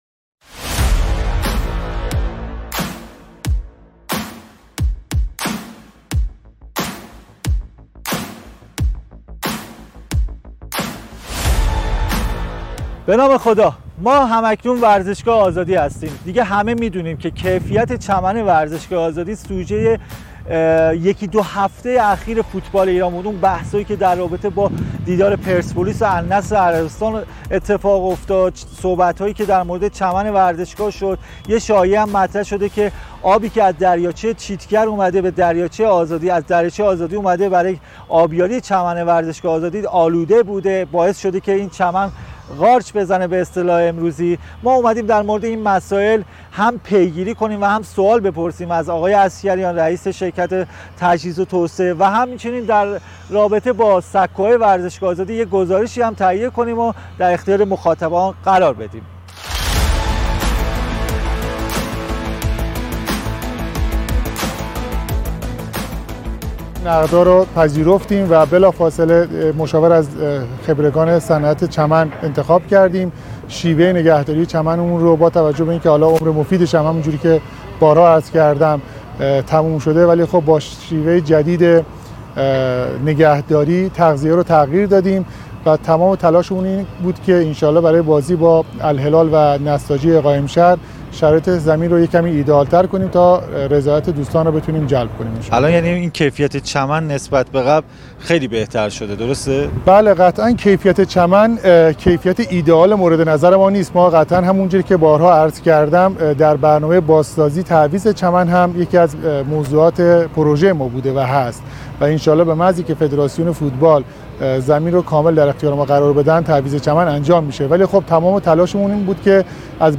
گزارش کامل از بازسازی و وضعیت چمن ورزشگاه آزادی